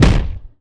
IMPACT_Generic_09_mono.wav